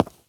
footstep_concrete_walk_20.wav